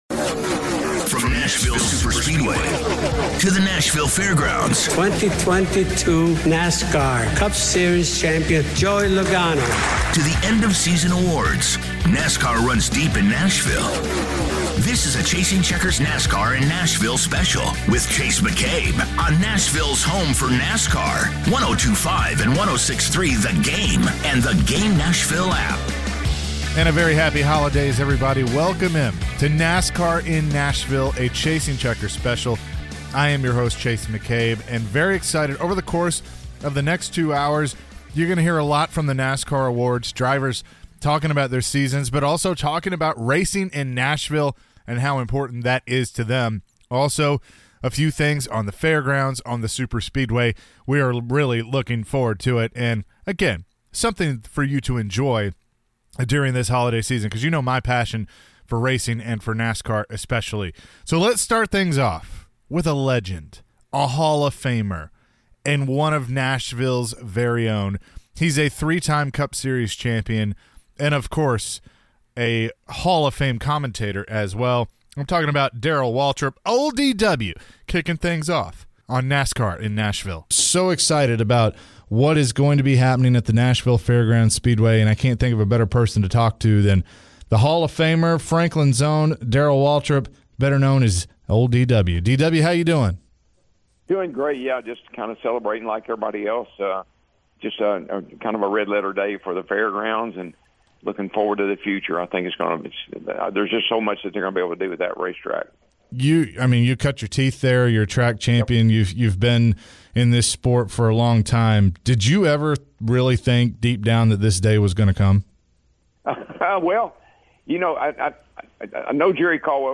talks to the drivers of NASCAR about both the Nashville Superspeedway and Nashville Fairgrounds, plus their seasons at the Annual NASCAR Awards.